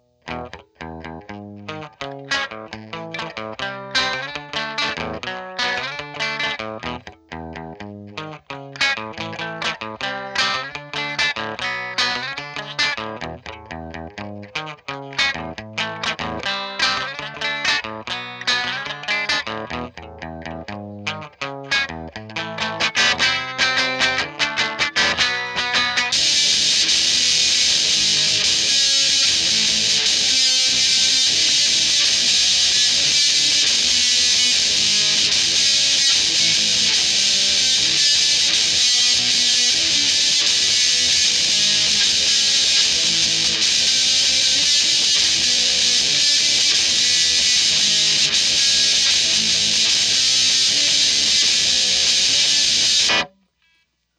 using the neck pickup of a fender strat.